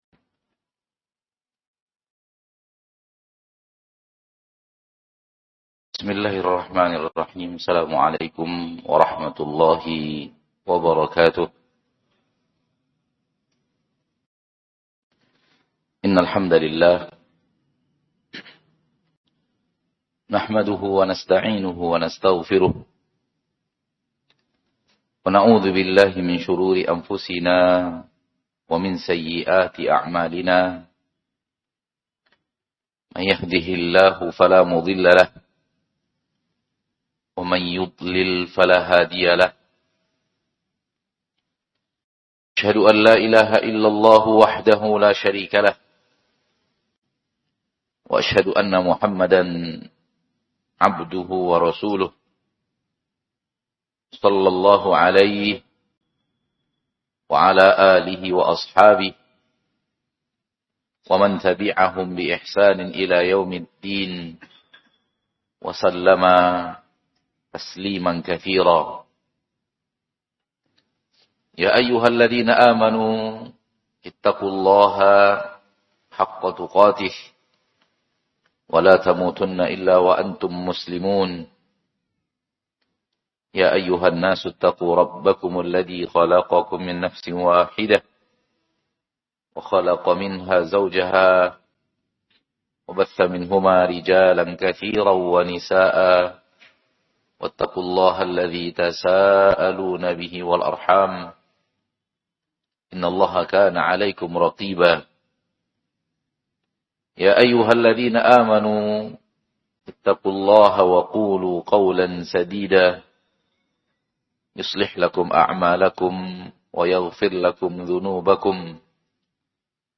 AUDIO KAJIAN